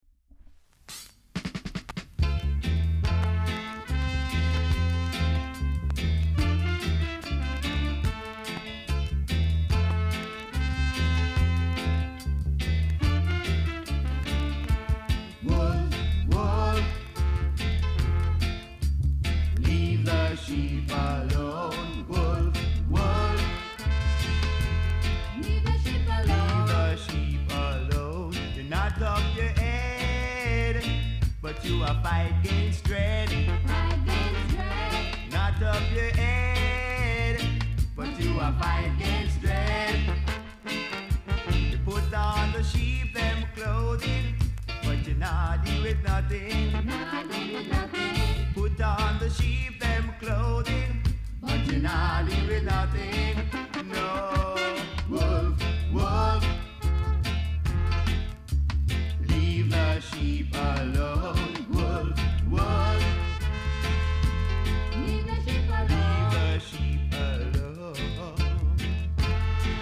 ROOTS CLASSIC!!!